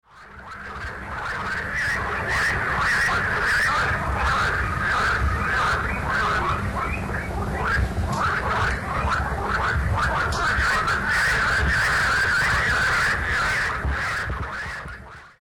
Ci-dessous, quelques extraits des différents types d'appels de la grenouille rieuse, Pelophylax ridibundus.
Ici un groupe de mâles se demandant si il y a de la concurrence, sorte de